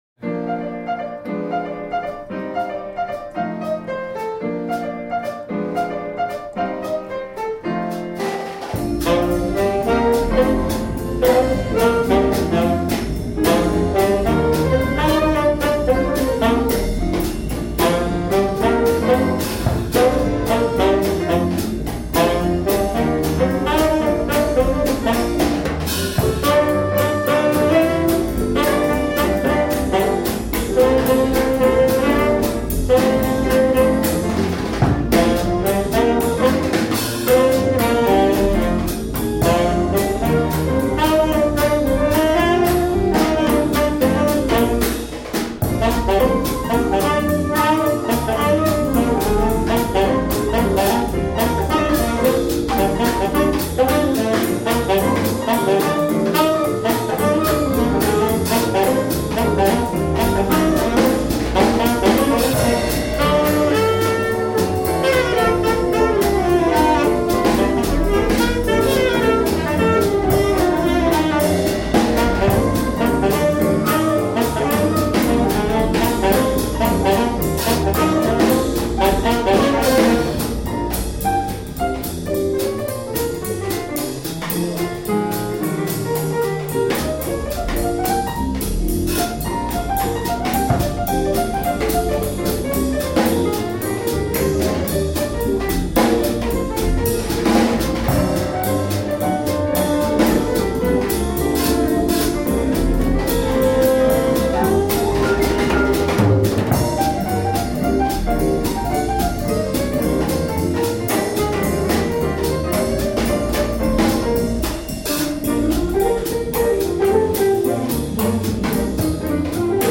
• Jazzbands